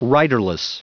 Prononciation du mot riderless en anglais (fichier audio)
Prononciation du mot : riderless